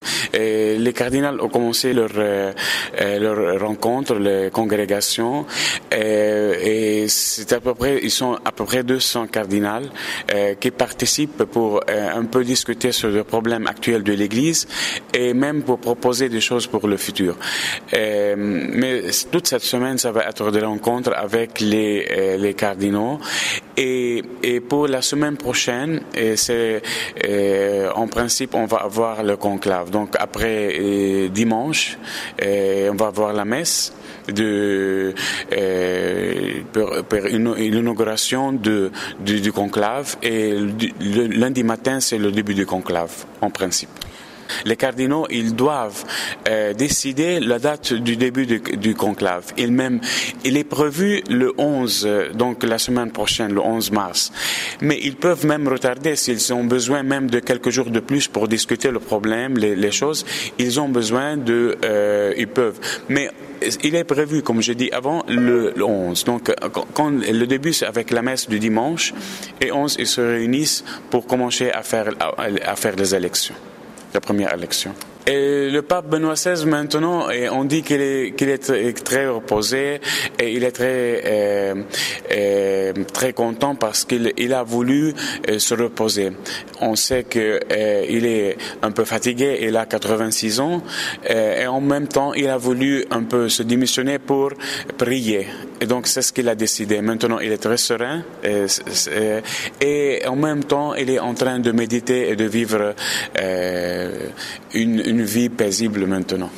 Deklarasyon